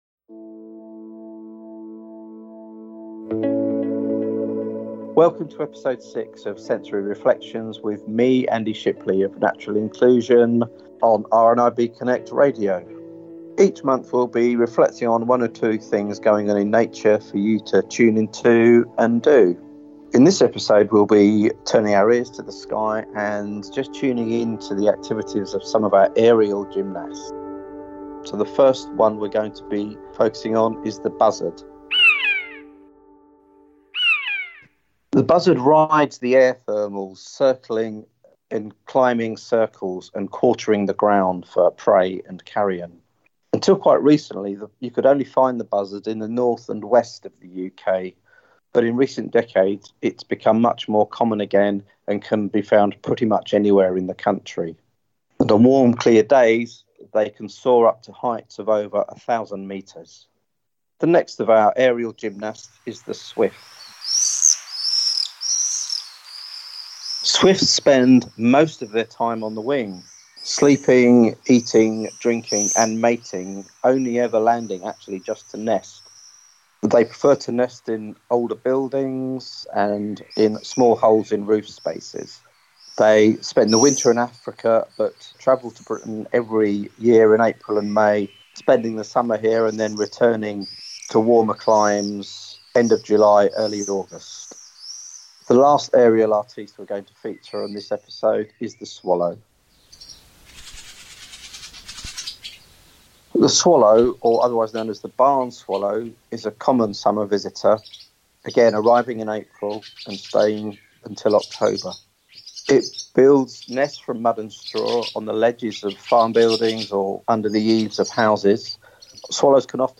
In this episode we will be turning our ears to the sky and tuning into some of our aerial gymnasts. Plus, a sensory activity and some ways you can help wildlife at this time of year.
Sound credits: 'COMMON SWIFT CALLS SOUND ' via Quick Sounds, ' BUZZARD SOUND ' via Quick Sounds, 'Swallows' via BigSoundBank.